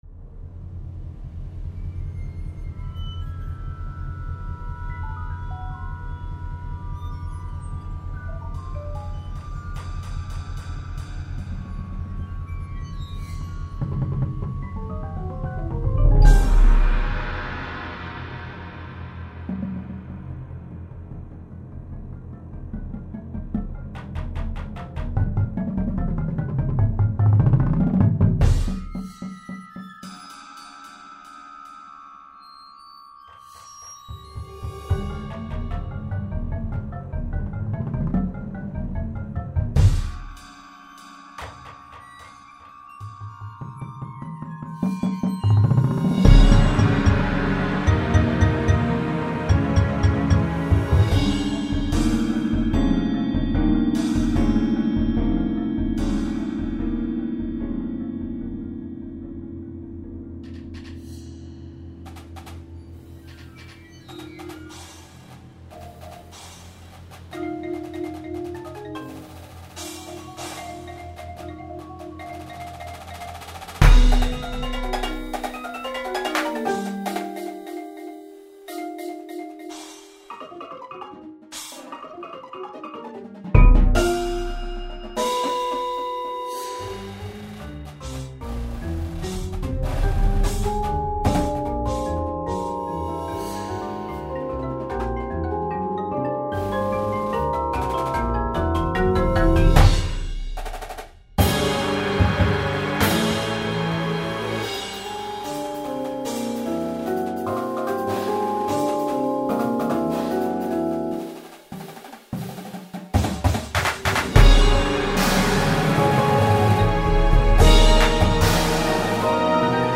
At times the music feels as if you are floating weightless.
Snares
Tenors (Sixes)
Bass Drums (4)
Marching Cymbals
Marimba 1, 2, 3
Xylophone/Bells/Crotales
Vibraphone 1, 2
Glockenspiel/Chimes
Synth 1, 2
Auxiliary Percussion 1, 2, 3